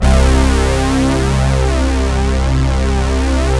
Index of /90_sSampleCDs/Sound & Vision - Gigapack I CD 2 (Roland)/SYN_ANALOG 1/SYN_Analog 2